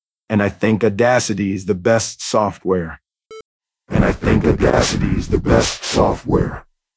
and an interesting “flanger-like” effect with the default settings: